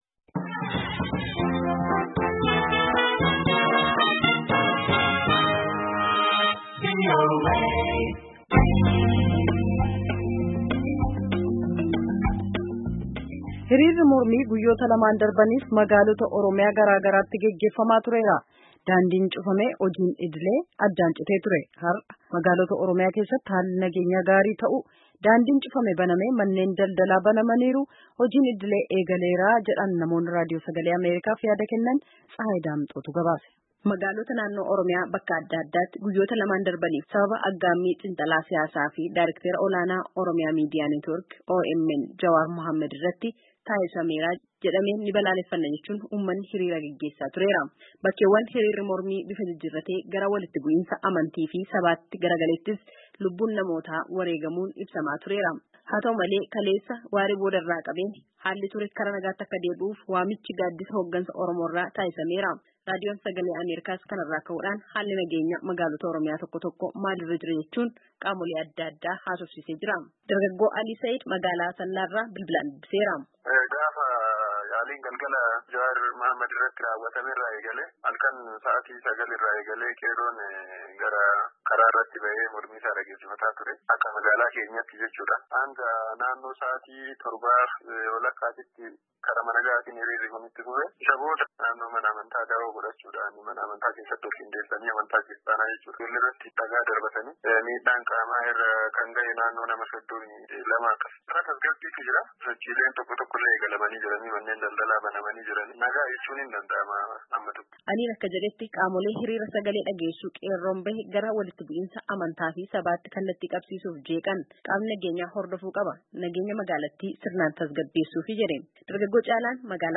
Hiriirri mormii guyyotaa lammaaf magaalalee Oromiyaa adda addaa keessatti geggeessamaa ture dhaabatee, daandiileen cufamanii turan banamuu fi hojiin addaan citee ture deebi’ee jalqabamuu jiraattoonni magaalaalee kanaa VOAtti himanii jiran.